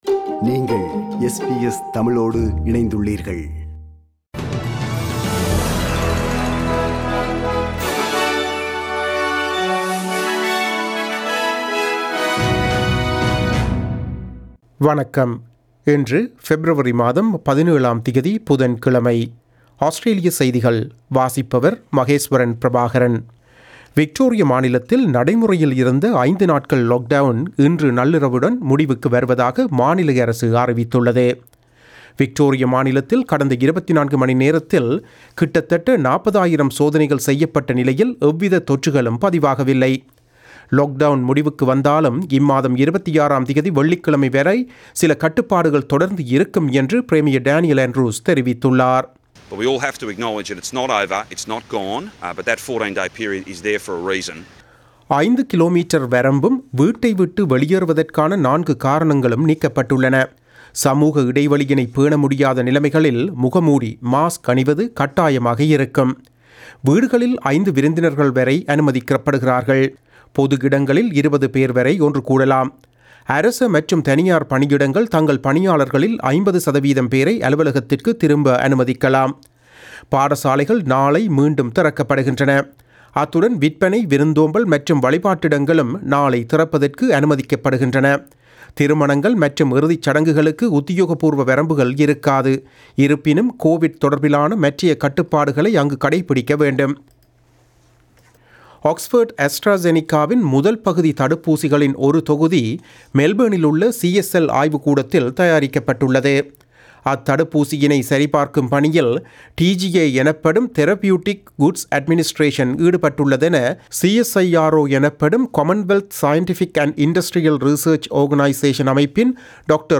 Australian news bulletin for Wednesday 17 February 2021.